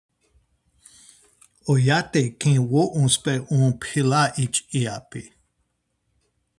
Lakota Title Pronunciation
Conference-Title-Pronunciation.m4a